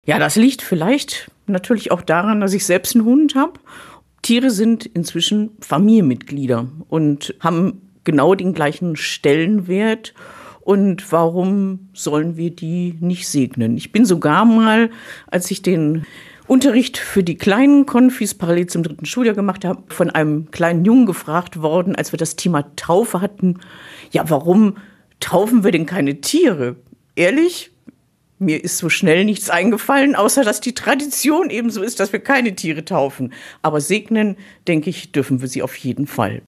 oton-tiersegnungsgottesdienst---wie-auf-die-idee-gekommen.mp3